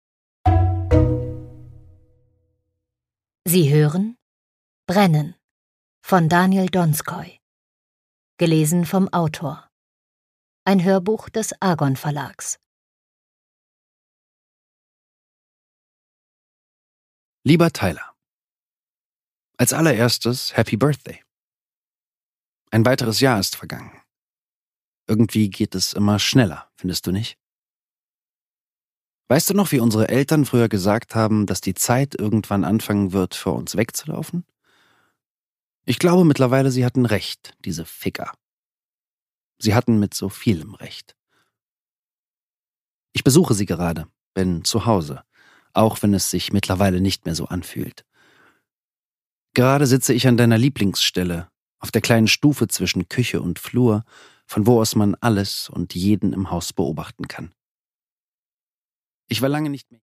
Daniel Donskoy: Brennen (Ungekürzte Lesung)
Produkttyp: Hörbuch-Download
Gelesen von: Daniel Donskoy